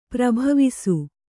♪ prabhavisu